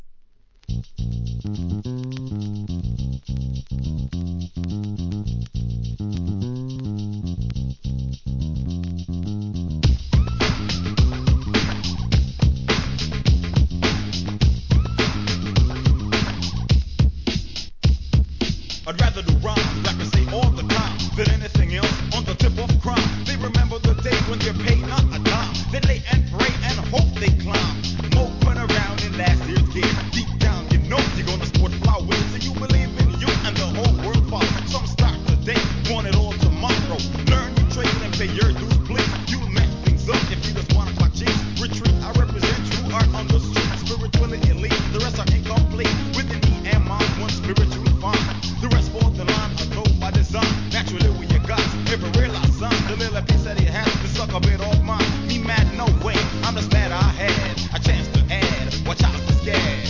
HIP HOP/R&B
1991年、定番ブレイクでのカナダ産マイナーHIP HOP!